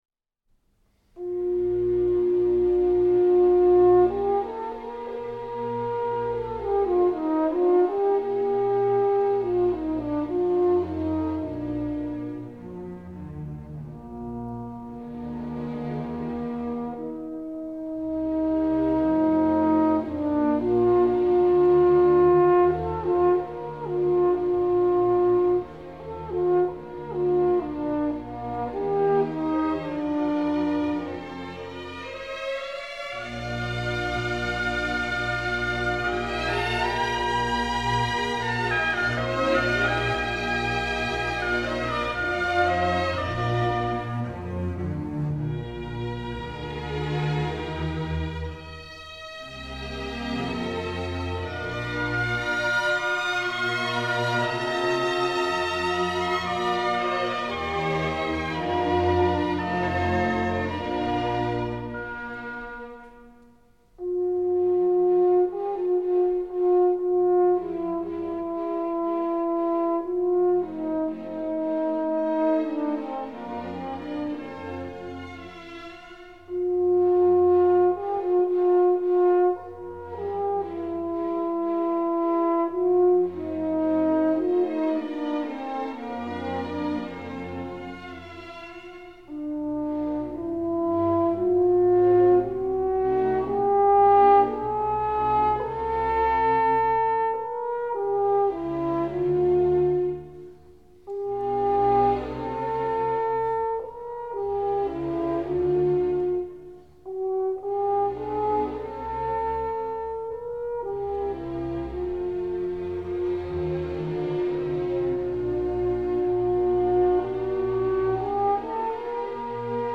A08-10 Horn Concerto No. 4 in E flat maj | Miles Christi
A08-10-Horn-Concerto-No.-4-in-E-flat-maj.mp3